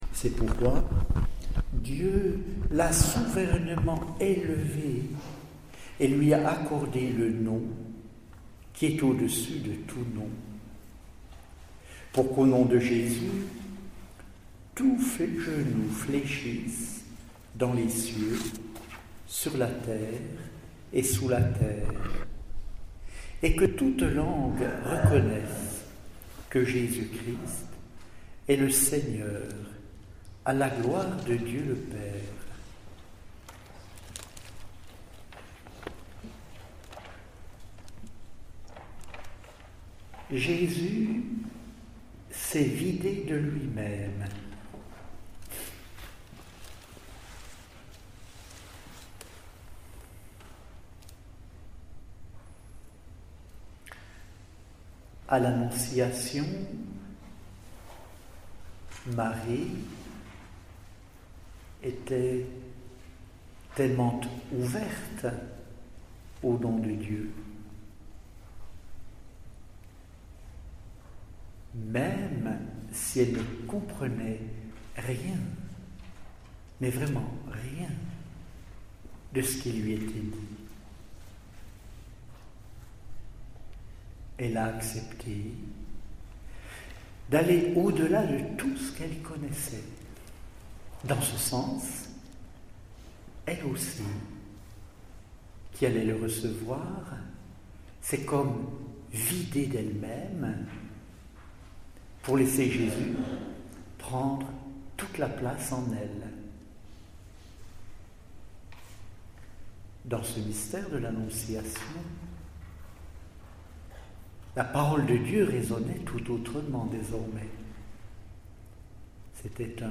(Week-end spirituel « Découvrir Saint Paul », mars 2009)
chapelet_medite_mysteres_joyeux_mars_2009_.mp3